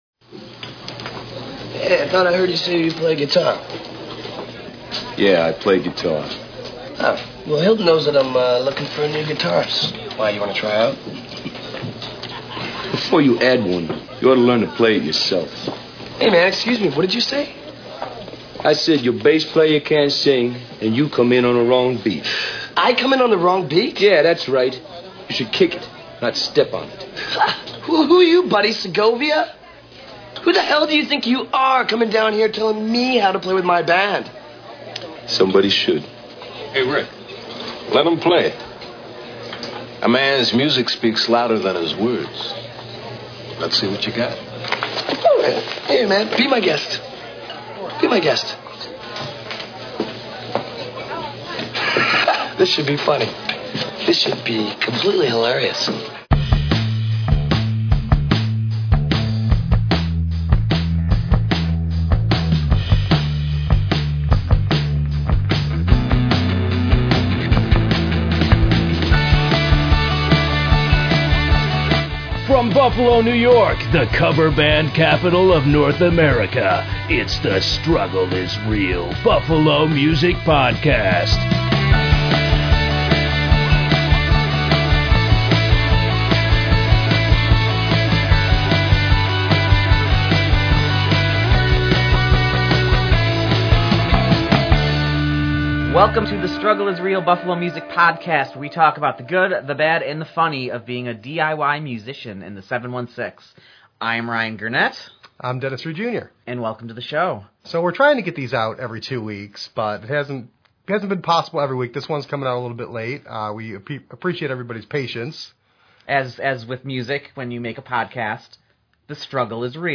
an in-depth interview